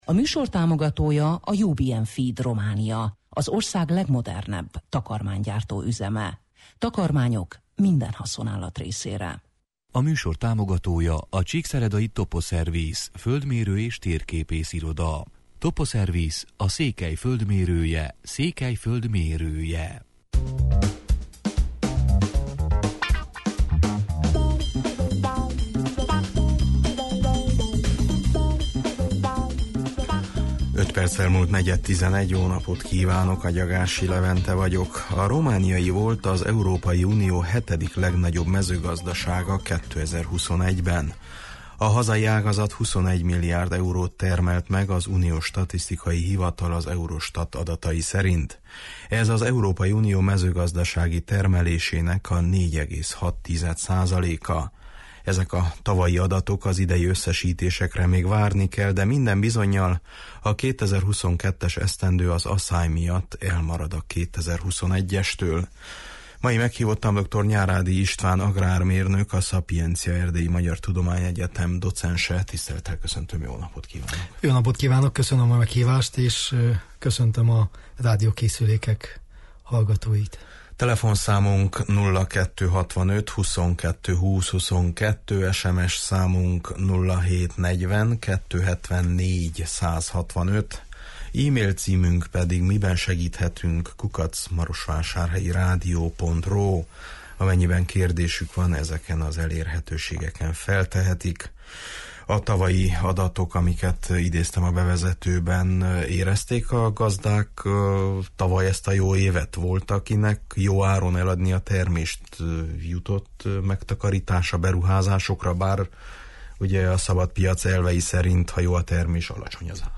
A romániai mezőgazdaság helyzetéről, versenyképességéről, agrároktatásról beszélgetünk mai műsorunkban.